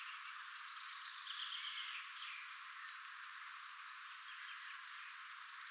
jotain hiljaista nopeaa, "kevyttä"
kuin punarinnan nopeaa tiksutusta
Hyvin hiljaisesti kuuluu.
mika_laji_tuollaista_hyvin_hiljaista_kuin_nopeaa_punarinnan_tiksutusta.mp3